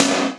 HR16B SNR 06.wav